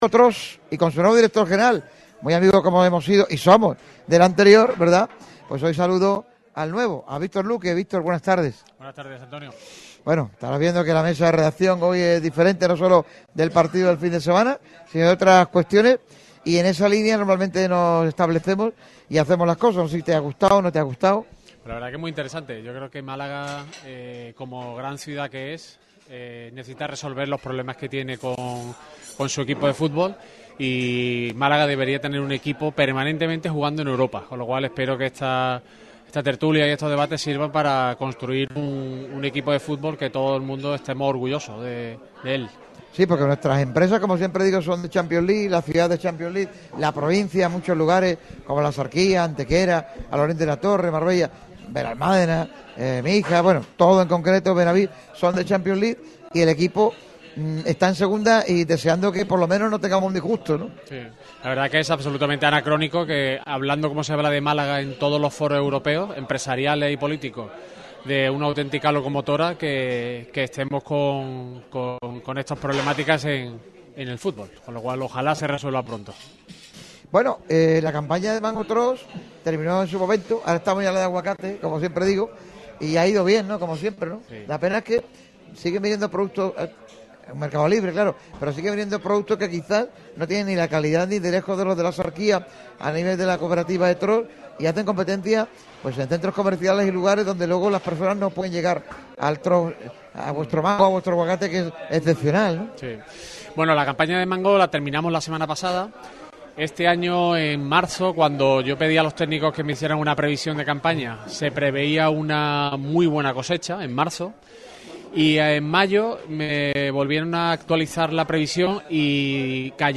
Radio MARCA Málaga ha estado presente en Asador Iñaki en un programa especial con el futuro del Málaga CF como plato protagonista.